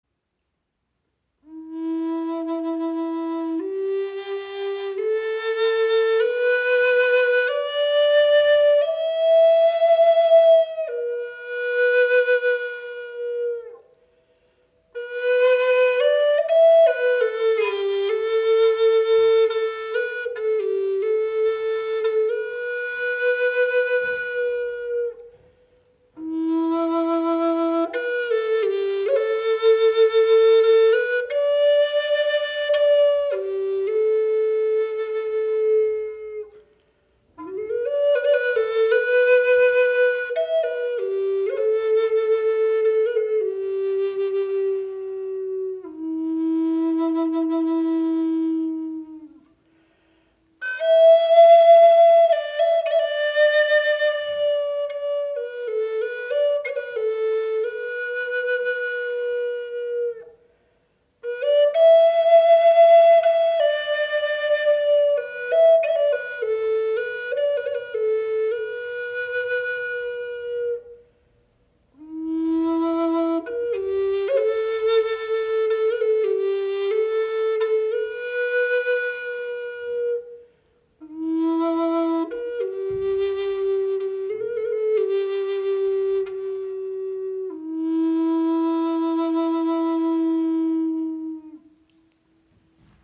Gebetsflöte High Spirit in E Moll | One-Inch Bore Bass Flöte
Die Tonart E-Moll wirkt erdend, beruhigend und tief entspannend.
Sie bietet einen warmen und tiefen Klang, der zugleich klar und brillant klingt.
Diese Condor Bass Flöte in E-Moll mit schmaler Ein-Zoll-Bohrung ist ideal für kleinere Hände und bietet einen warmen, tiefen Klang.